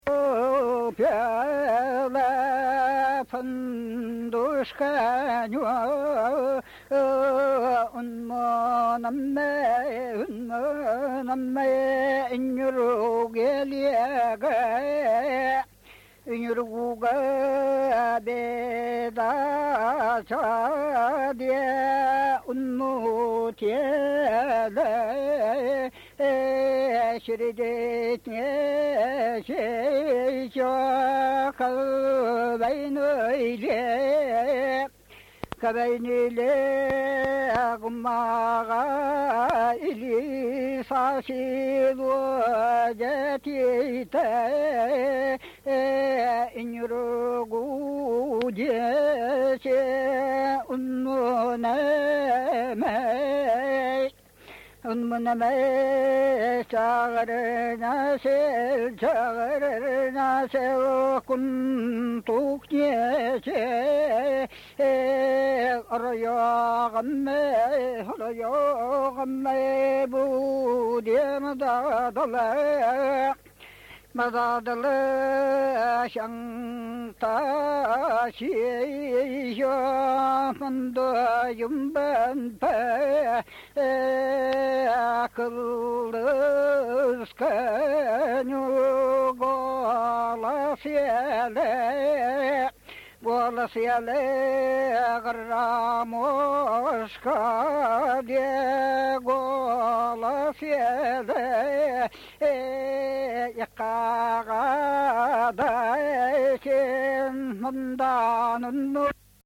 This is another example of an individual song that describes a journey on the river Kolyma.